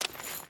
Footsteps / Water
Water Chain Run 1.wav